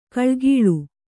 ♪ kaḷgīḷu